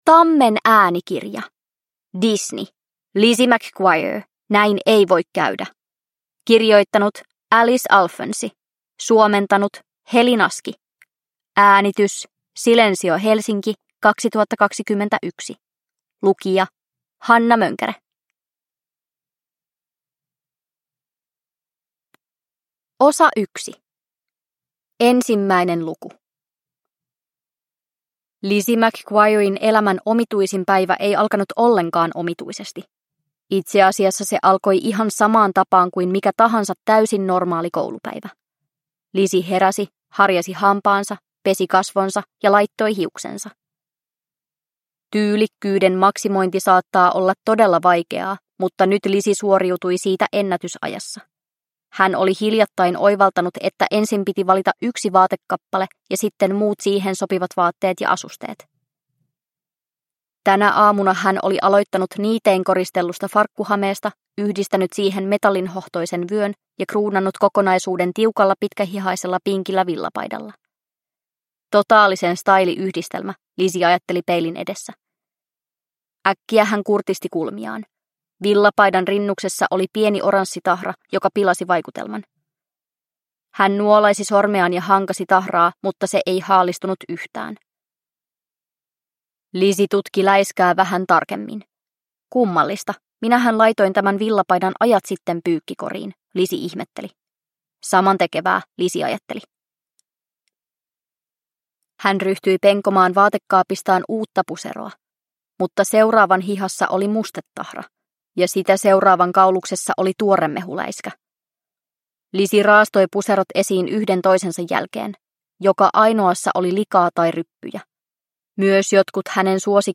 Lizzie McGuire. Näin ei voi käydä – Ljudbok – Laddas ner